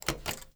BUTTON_Spring_Press_mono.wav